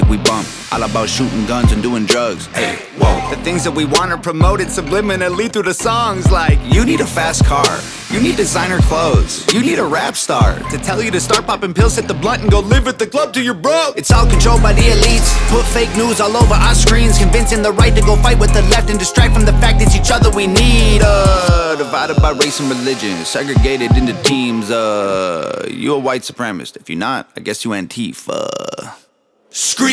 • Hip-Hop/Rap